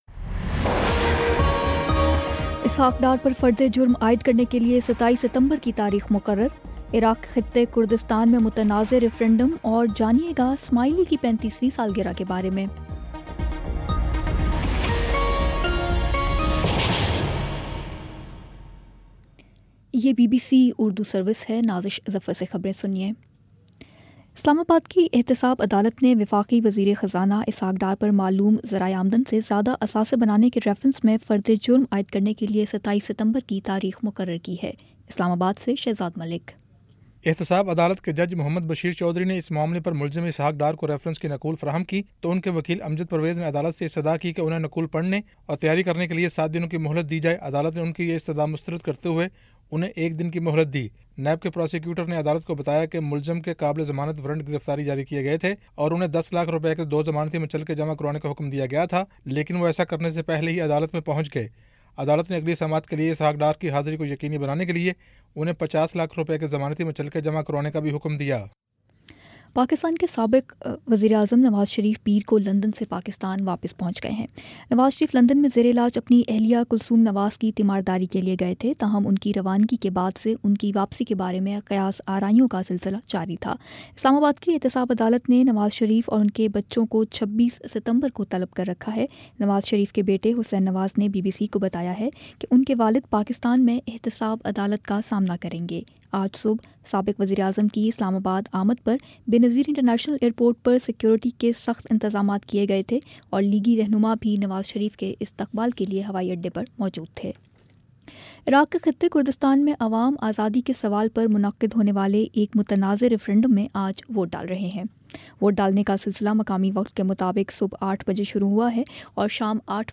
ستمبر 25 : شام چھ بجے کا نیوز بُلیٹن